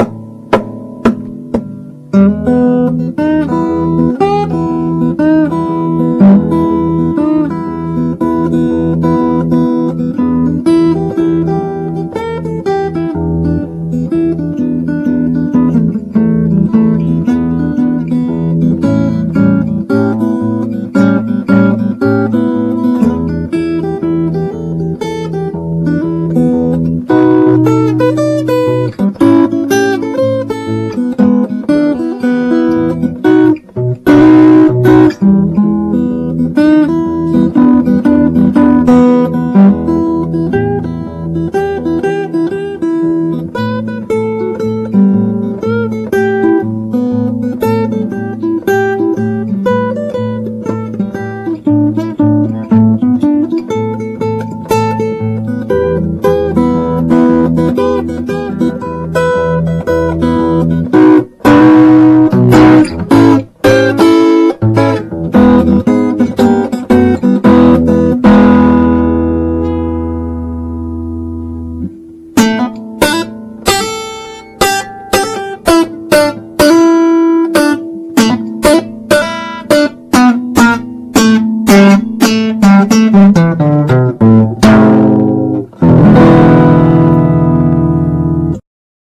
BlueswithOnly1Guitar.rm